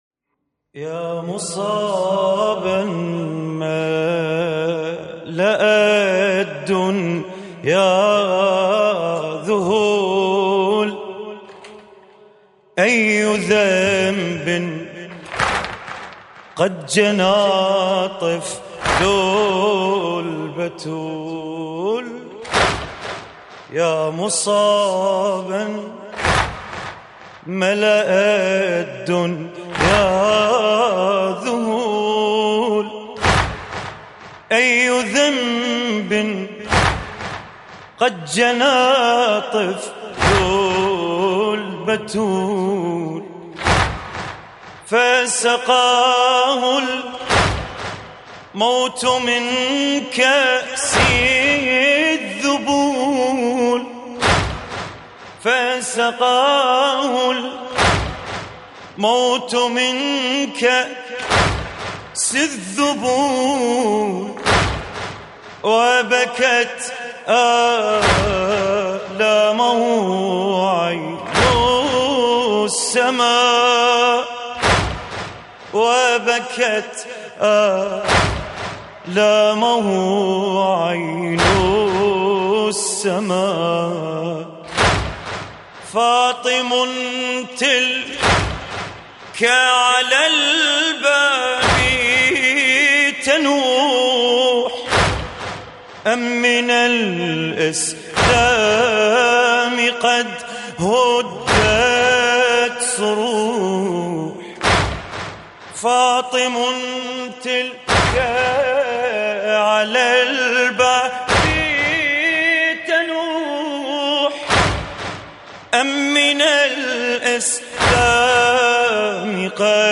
مراثي فاطمة الزهراء (س)